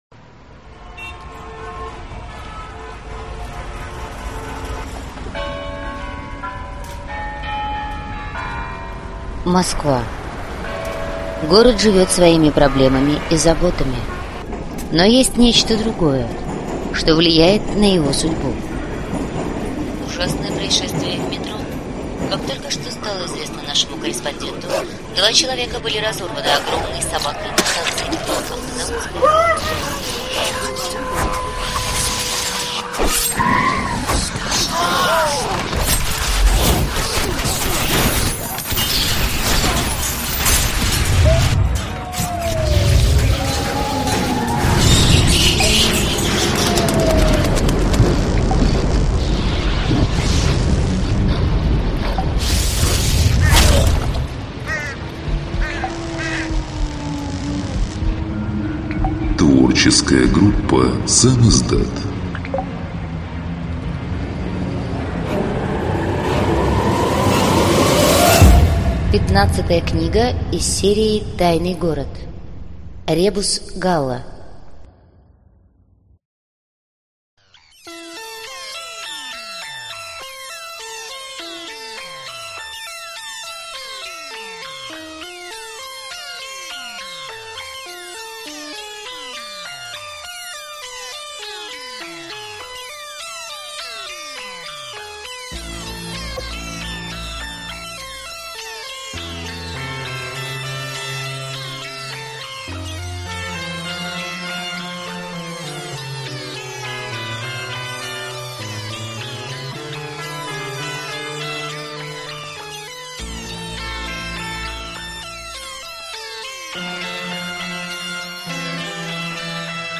Аудиокнига Ребус Галла - купить, скачать и слушать онлайн | КнигоПоиск